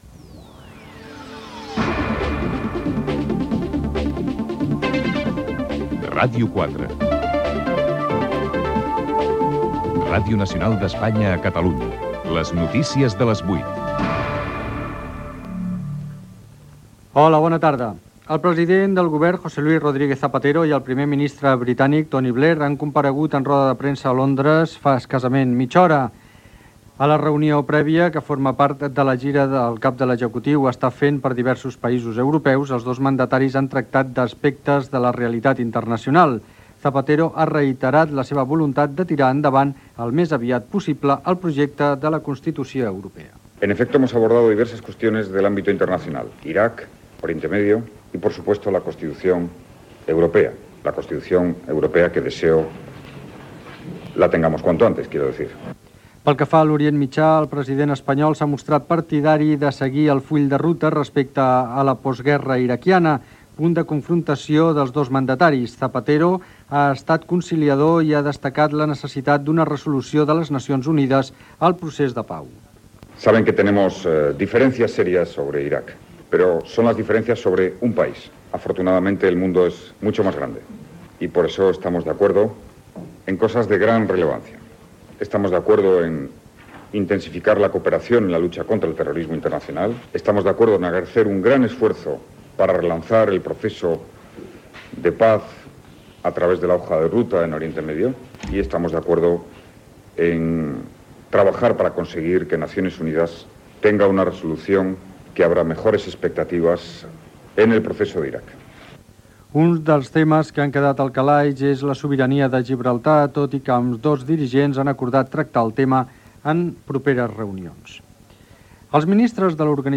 Trobada dels presidents Blair i Zapatero a Londres; acord de l'OPEP; govern interí a l'Iraq; Servei Català de Trànsit. Indicatiu de l'emissora, promo de "Catalunya exprés", indicatiu de l'emissora.
Informatiu